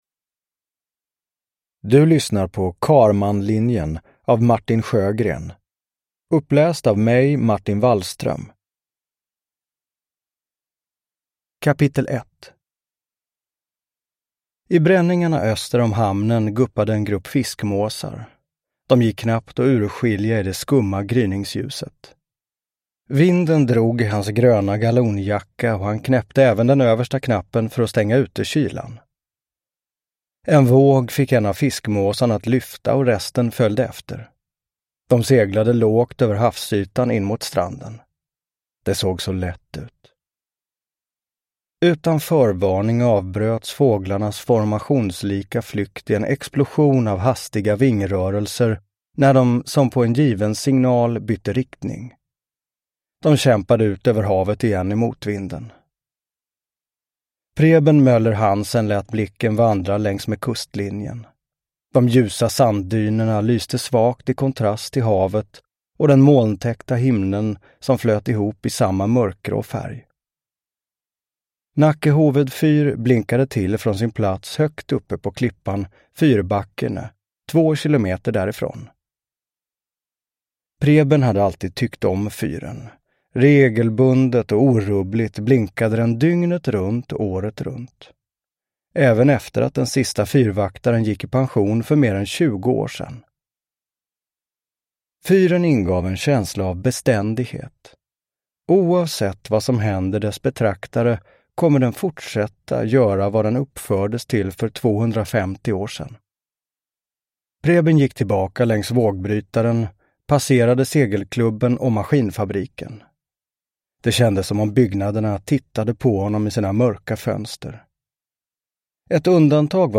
Karmanlinjen – Ljudbok
Uppläsare: Martin Wallström